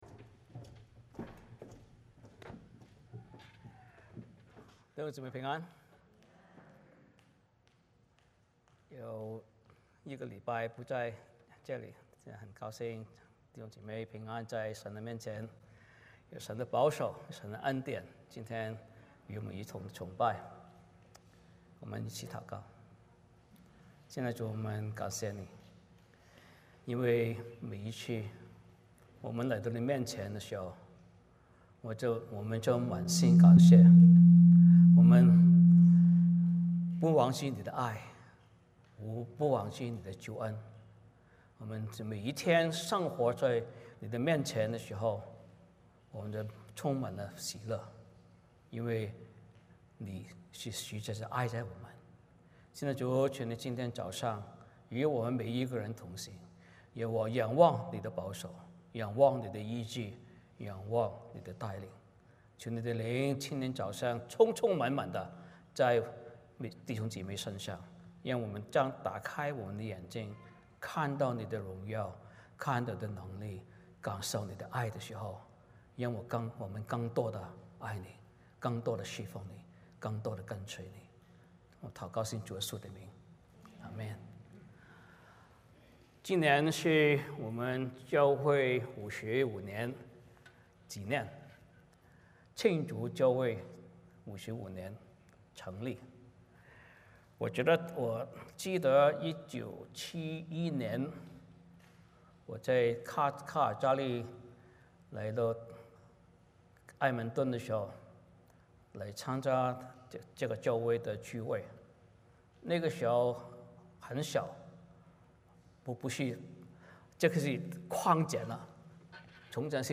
欢迎大家加入我们国语主日崇拜。
1-7 Service Type: 主日崇拜 欢迎大家加入我们国语主日崇拜。